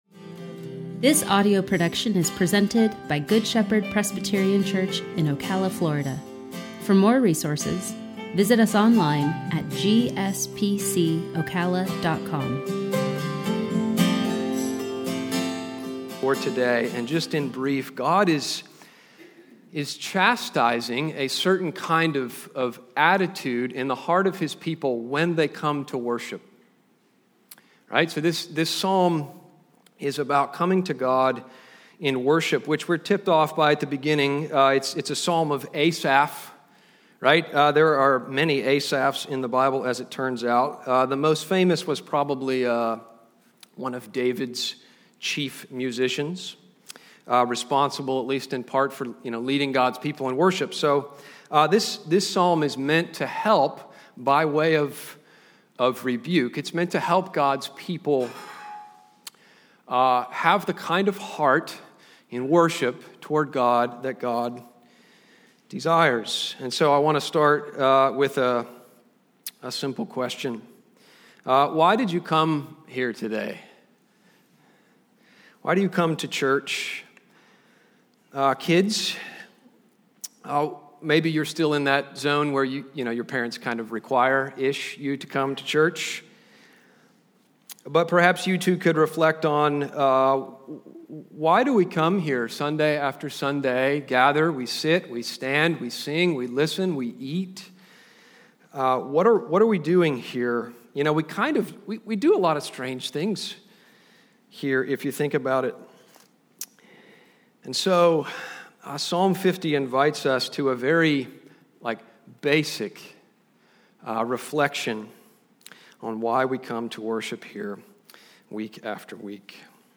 Sermon-7-11-21.mp3